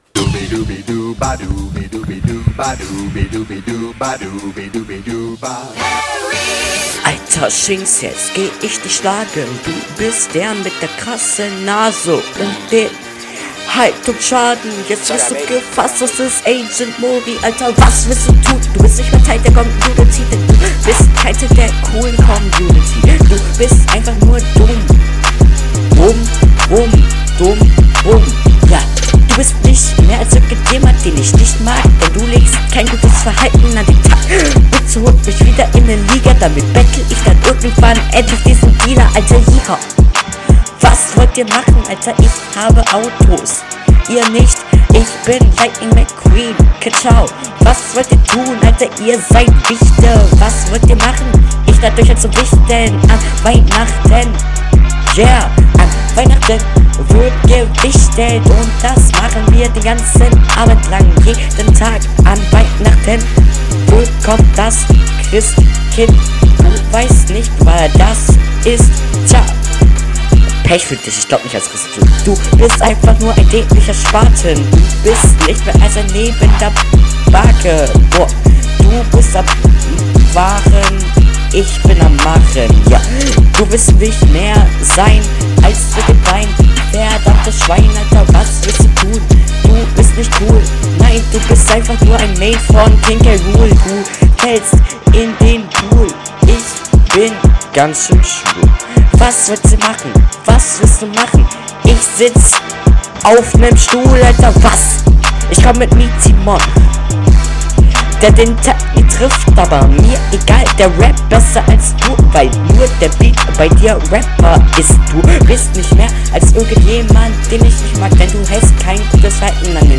Battle Runden